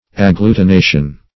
Agglutination \Ag*glu`ti*na"tion\, n. [Cf. F. agglutination.]